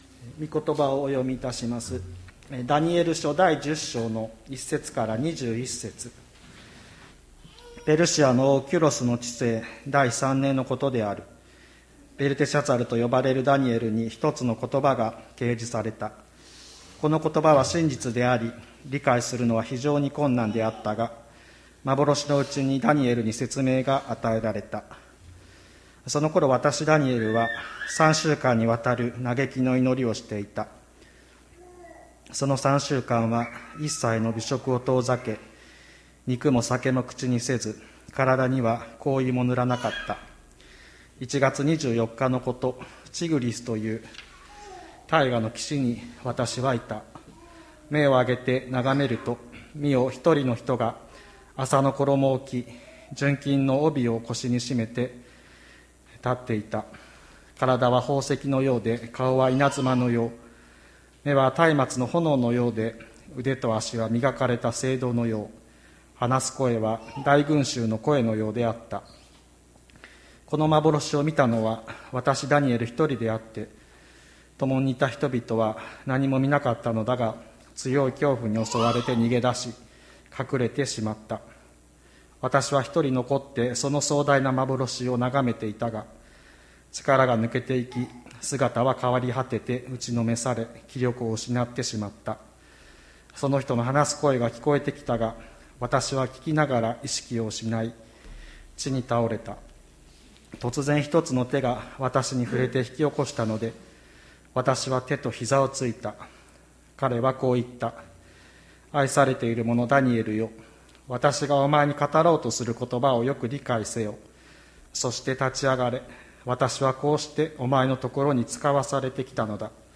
2021年03月28日朝の礼拝「祈りは聞かれている」吹田市千里山のキリスト教会
千里山教会 2021年03月28日の礼拝メッセージ。